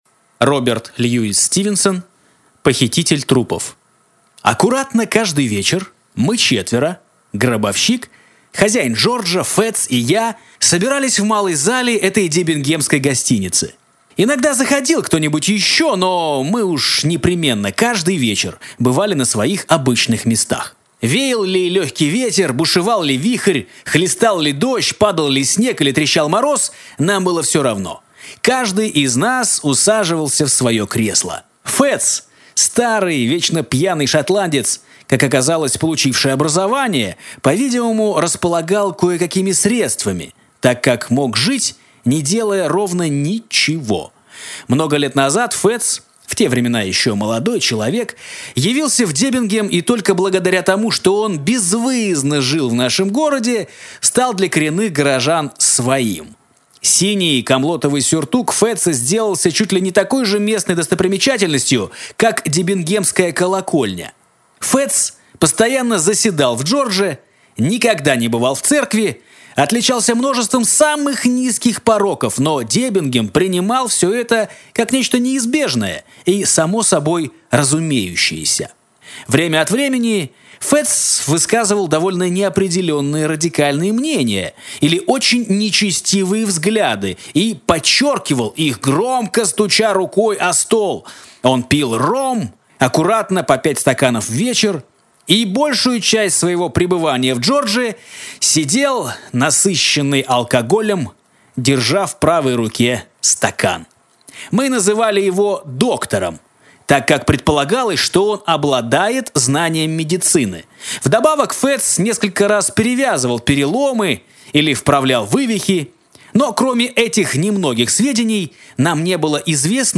Аудиокнига Похититель трупов | Библиотека аудиокниг